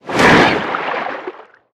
Sfx_creature_snowstalkerbaby_flinch_swim_02.ogg